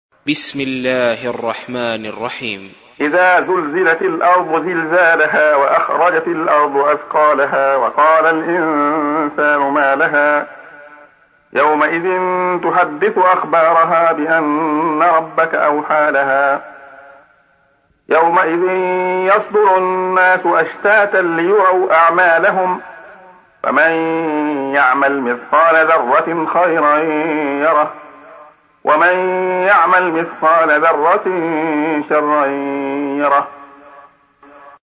تحميل : 99. سورة الزلزلة / القارئ عبد الله خياط / القرآن الكريم / موقع يا حسين